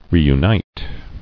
[re·u·nite]